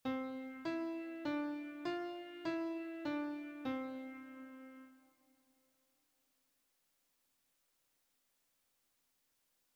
simpleTimeSig.mp3